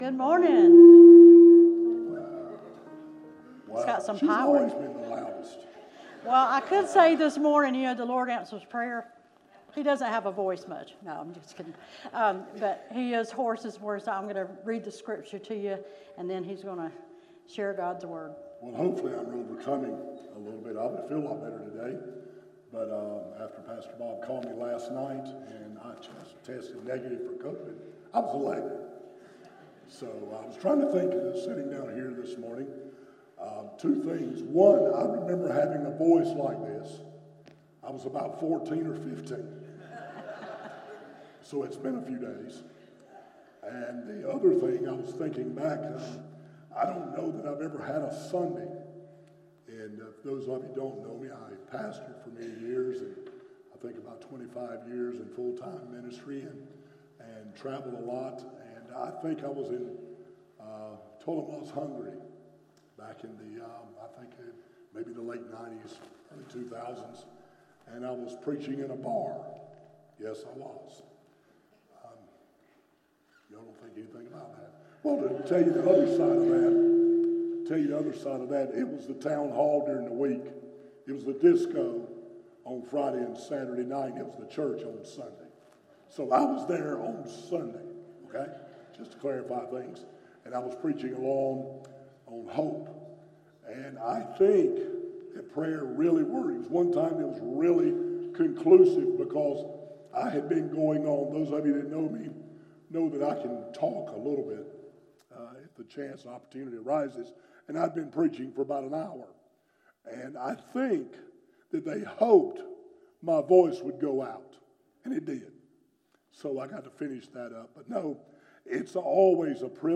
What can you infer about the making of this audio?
From Series: "Morning Worship - 11am"